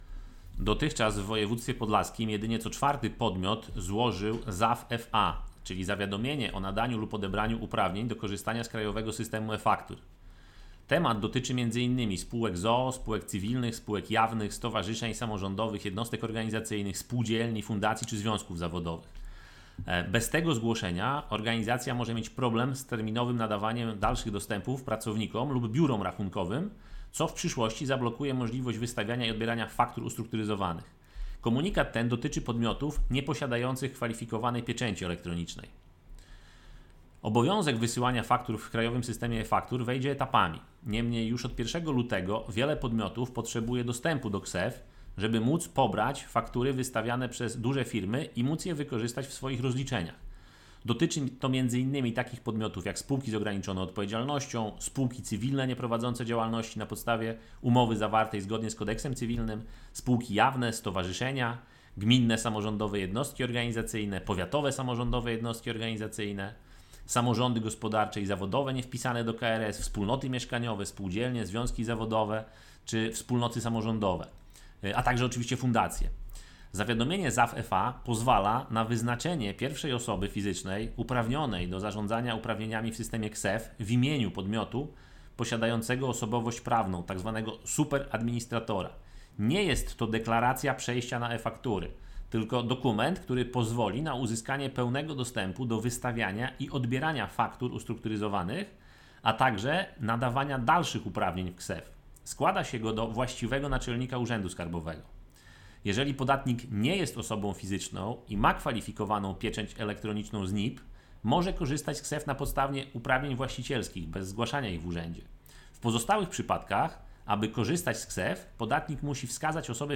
Kto powinien złożyć ZAW-FA – wypowiedź